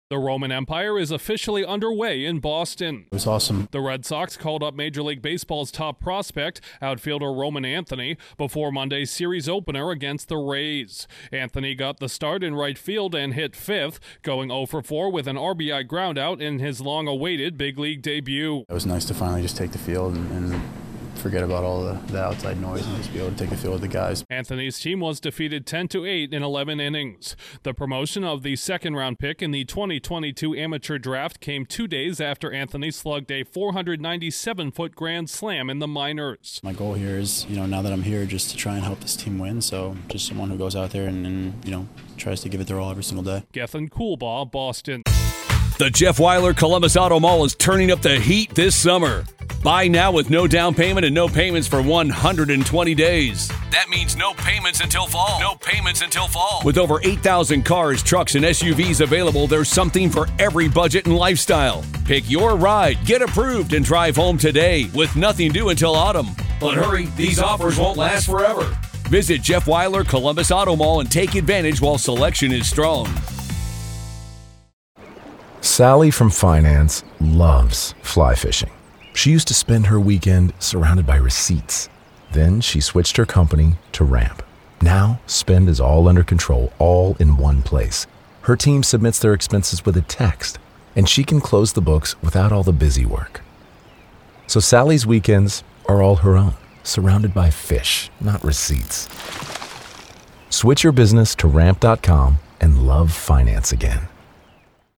he most highly-regarded prospect in baseball has debuted at one of the game’s most iconic venues in Fenway Park. Correspondent